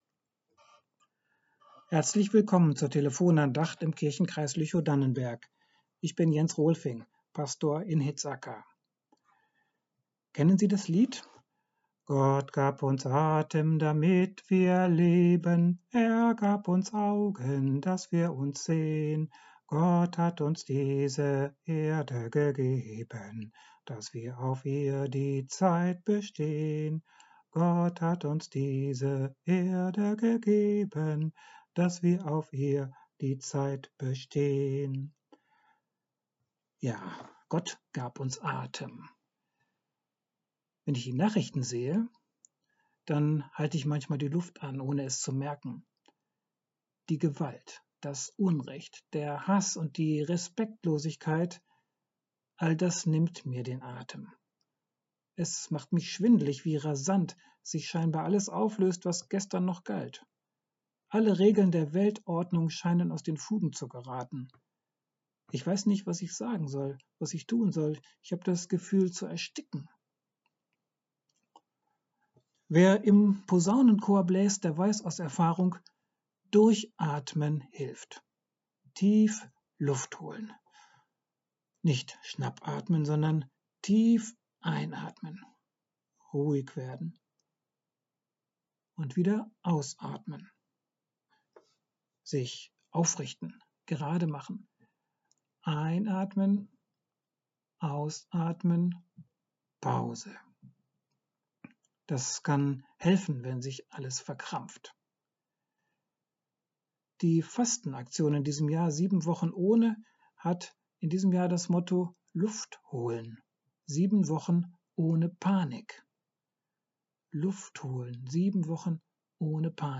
Luft holen. 7 Wochen ohne Panik ~ Telefon-Andachten des ev.-luth. Kirchenkreises Lüchow-Dannenberg Podcast